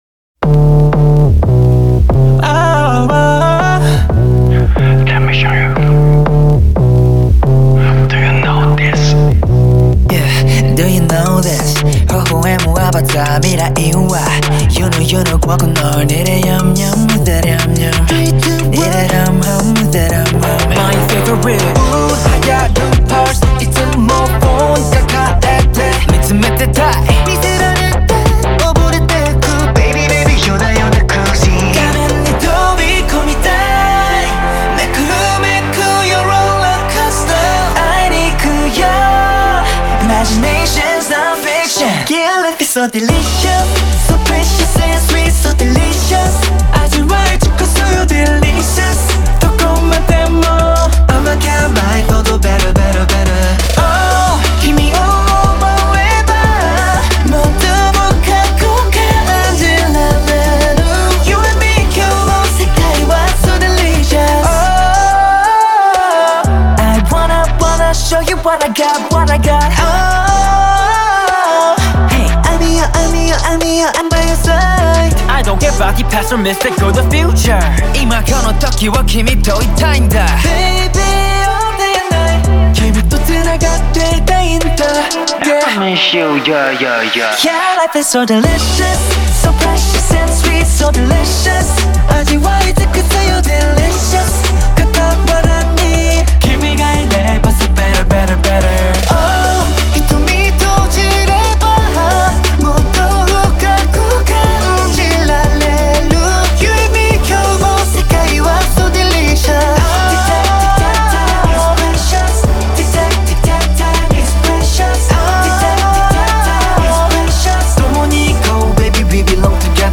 BPM90-180
Audio QualityPerfect (High Quality)